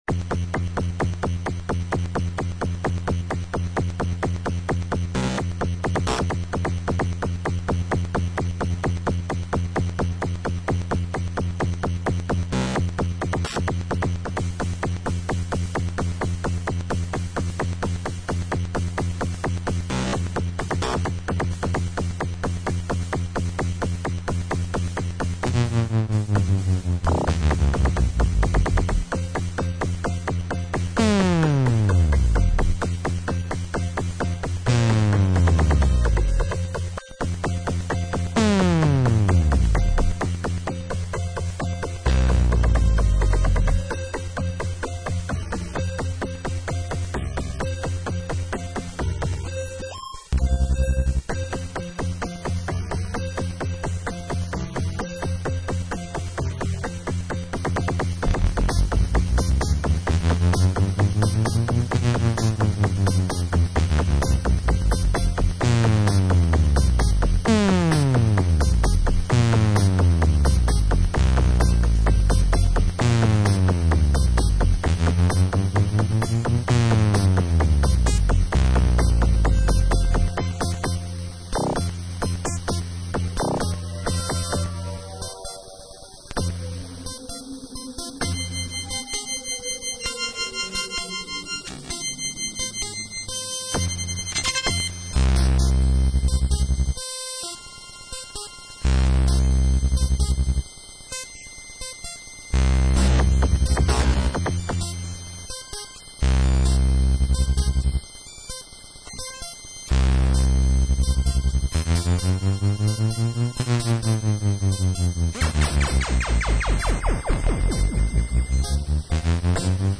“ an anomalous sampling of bleepy techno sputter.